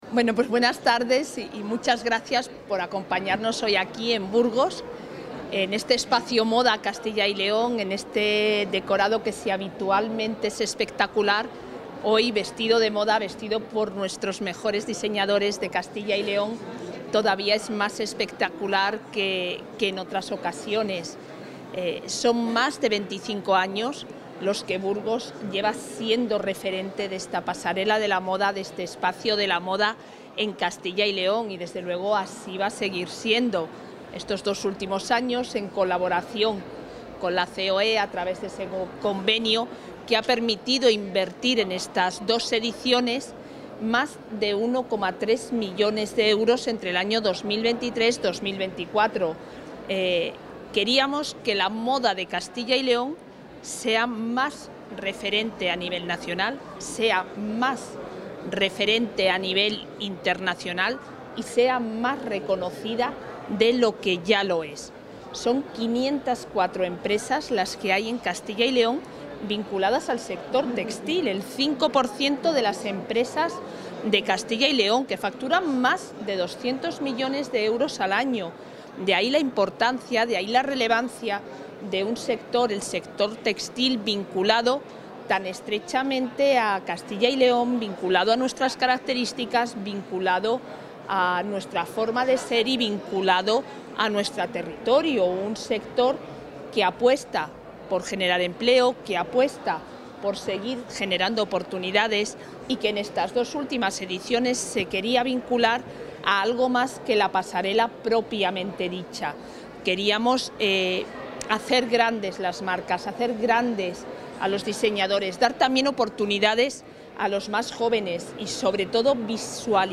Intervención del consejero de Economía y Hacienda.